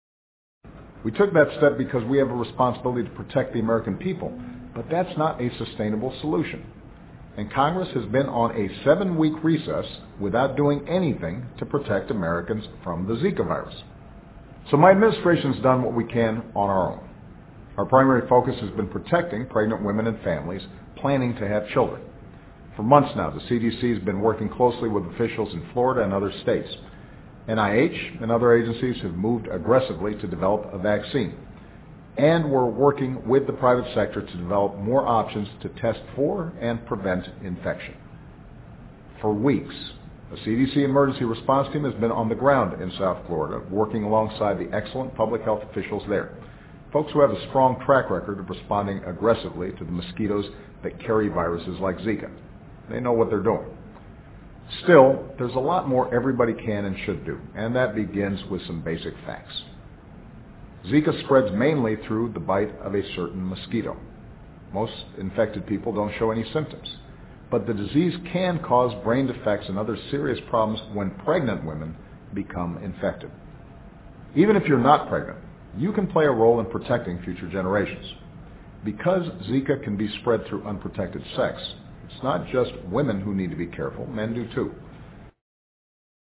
奥巴马每周电视讲话：总统呼吁采取措施防控寨卡病毒（02） 听力文件下载—在线英语听力室